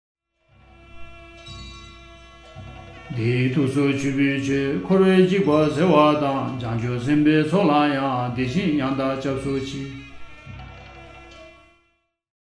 Karma_BCA_Chapter_2_verse_48_with_music.mp3